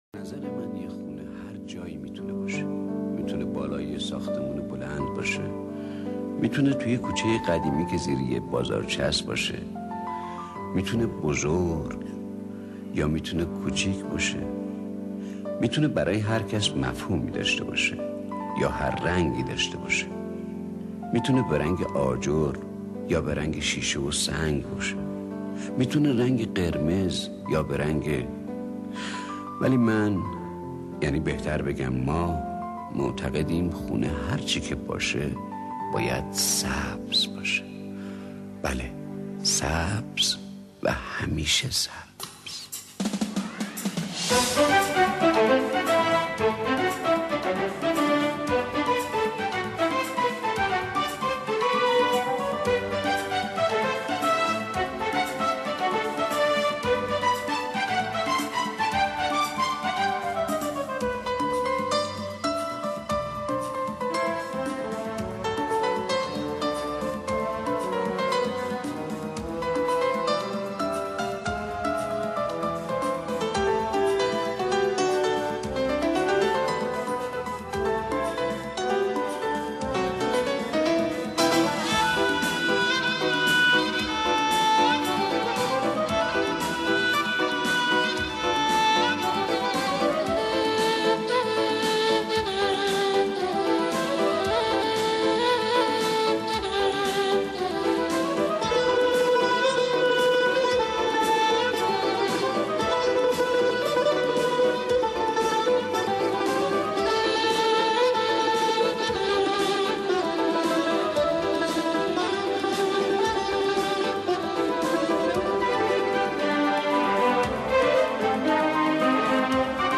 دانلود دکلمه خانه سبز با صدای خسرو شکیبایی
گوینده :   [خسـرو شکیبایی]